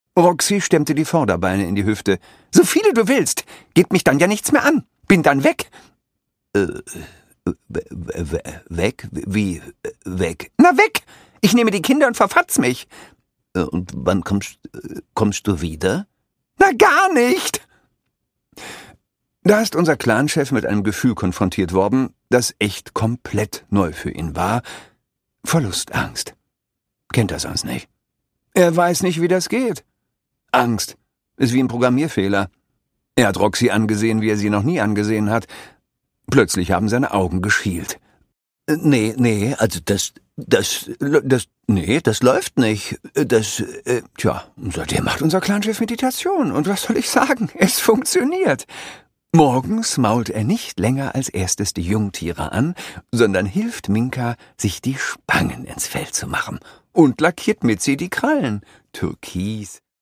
Produkttyp: Hörbuch-Download
Gelesen von: Christoph Maria Herbst